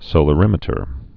(sōlə-rĭmĭ-tər)